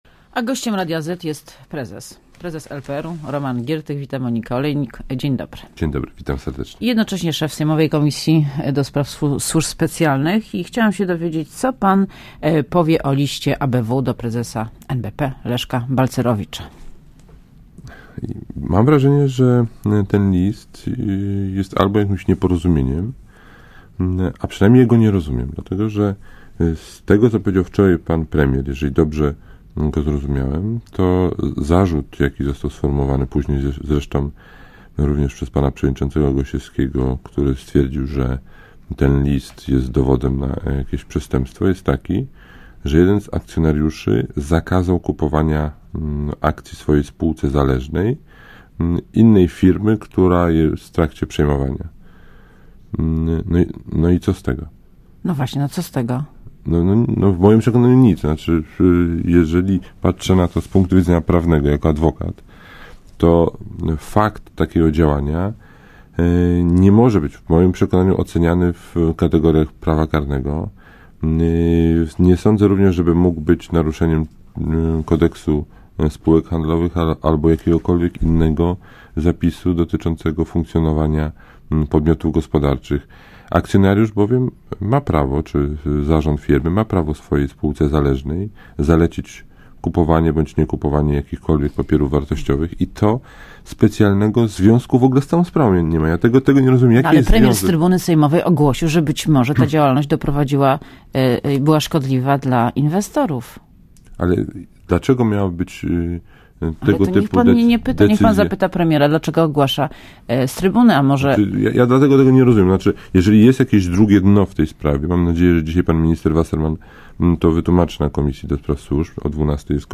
© (Archiwum) Posłuchaj Wywiadu Gościem Radia ZET jest prezes, prezes LPR-u Roman Giertych .